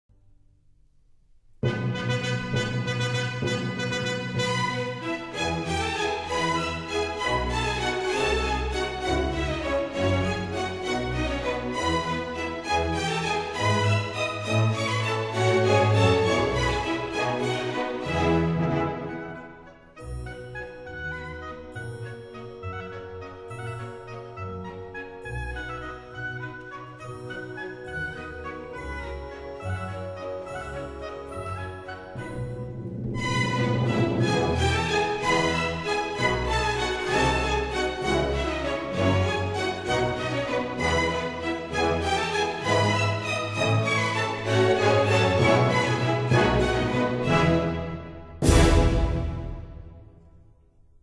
Allegretto pesante
沉重地稍快板<00:51>